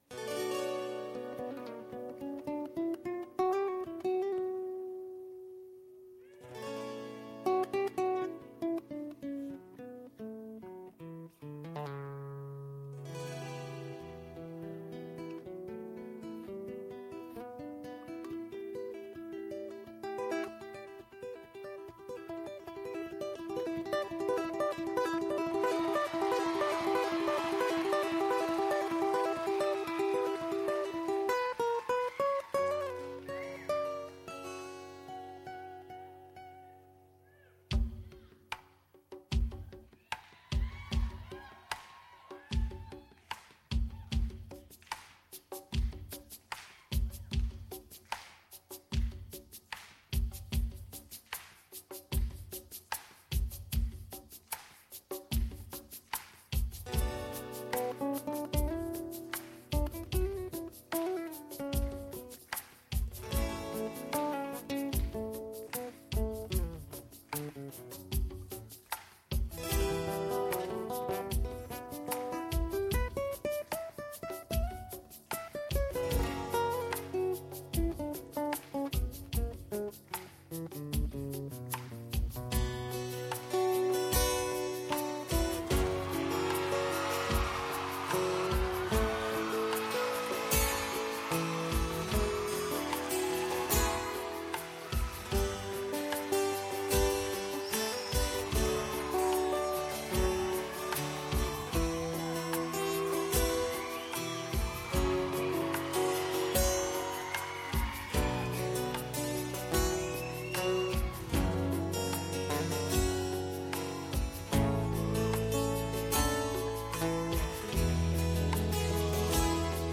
Your song should be mono audio rather than stereo.